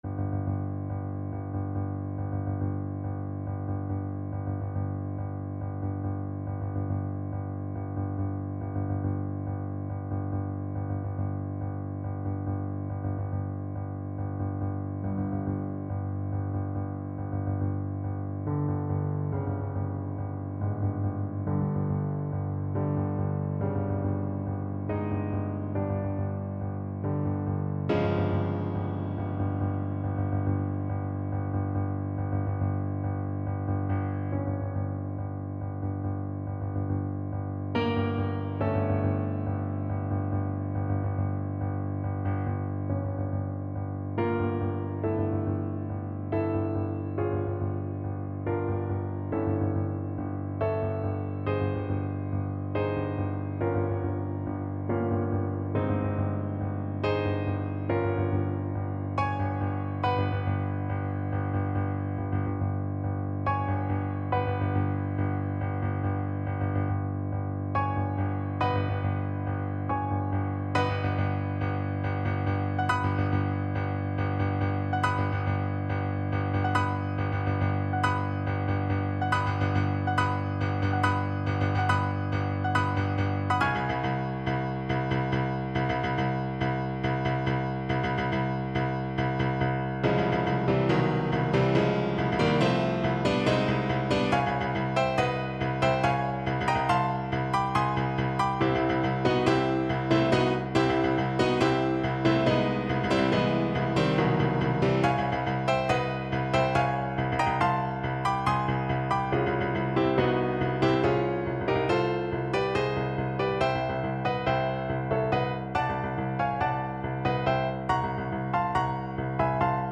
5/4 (View more 5/4 Music)
Allegro = 140 (View more music marked Allegro)
Classical (View more Classical Cello Music)